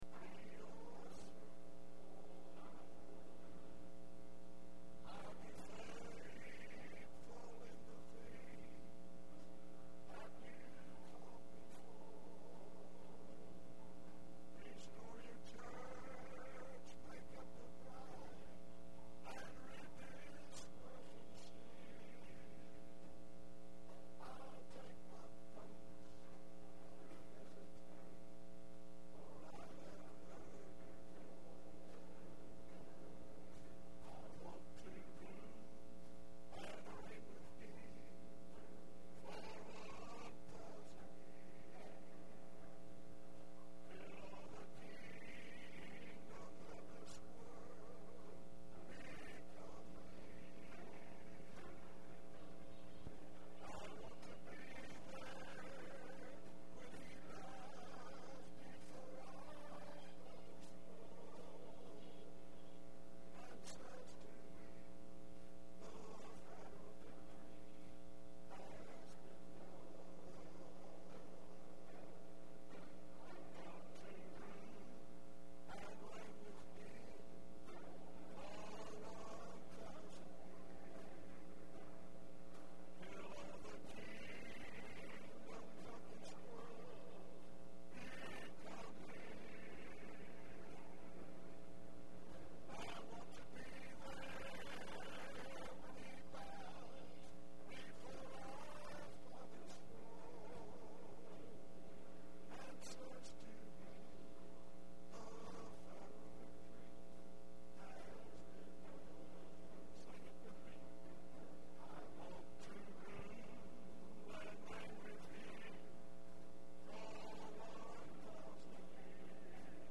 6/2/10 Wednesday Service